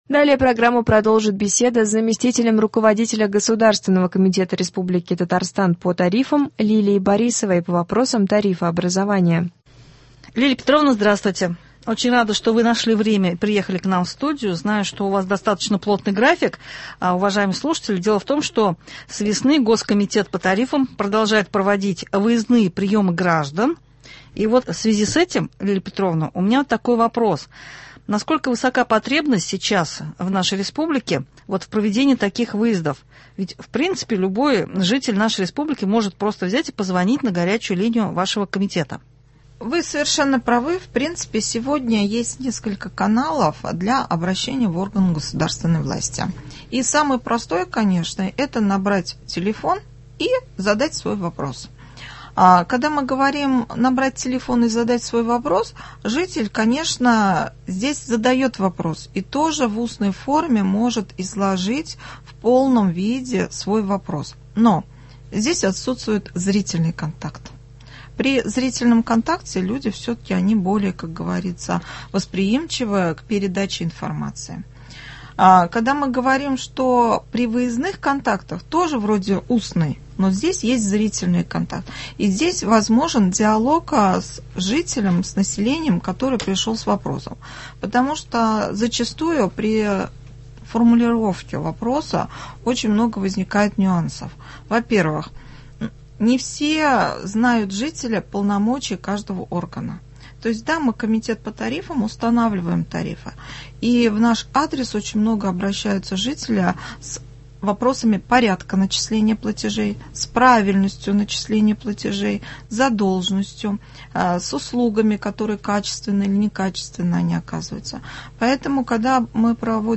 Беседа с заместителем руководителя государственного комитета РТ по тарифам Лилией Борисовой по вопросам тарифообразования.